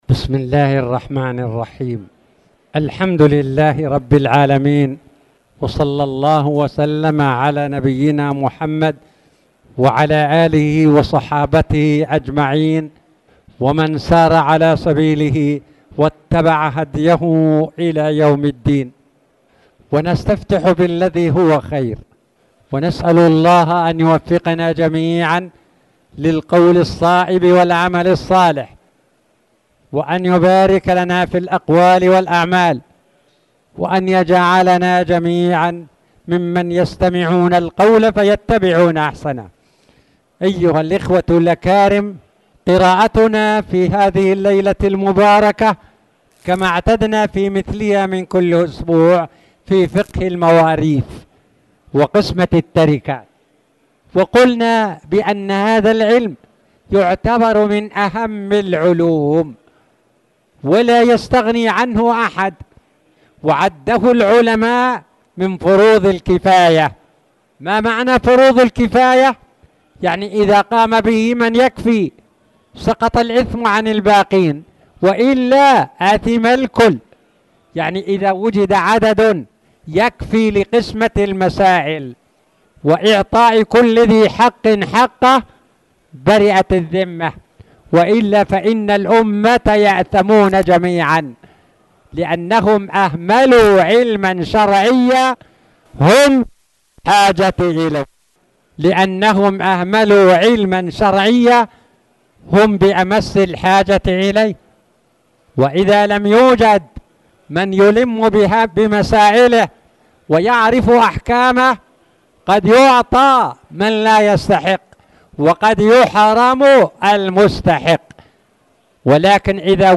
تاريخ النشر ١٤ جمادى الآخرة ١٤٣٨ هـ المكان: المسجد الحرام الشيخ